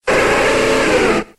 Cri de Feunard dans Pokémon X et Y.